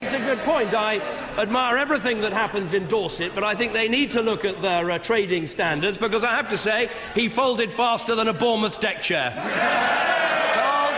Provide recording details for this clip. PMQs, 11 September 2013